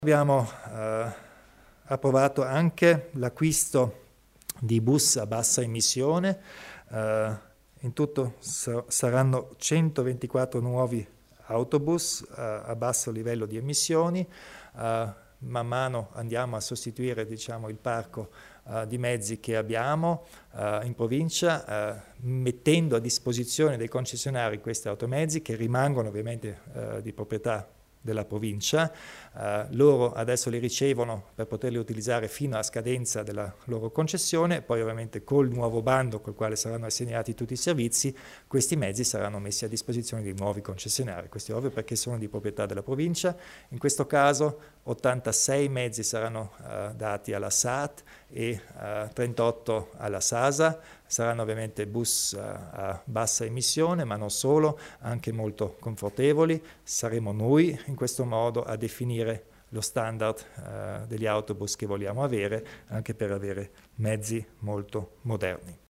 Il Presidente Kompatscher spiega gli investimenti a favore del trasporto pubblico